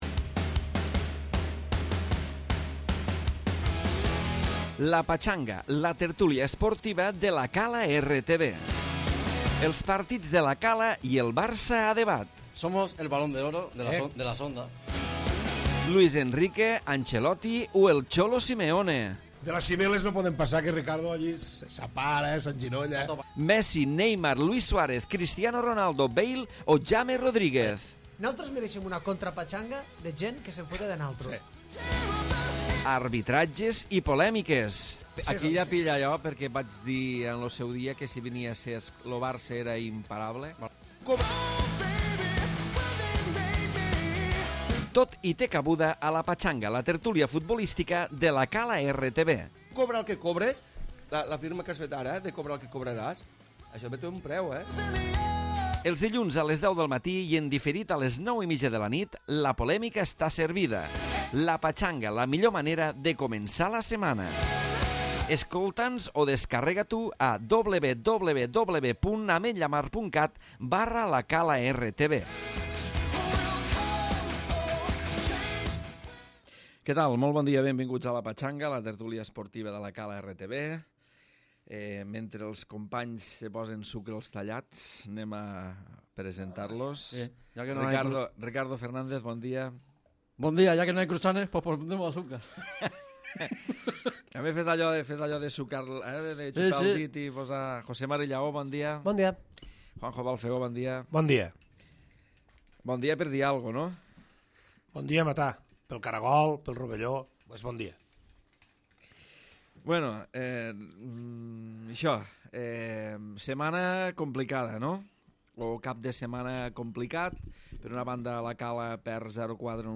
La tertúlia esportiva a la Calartv.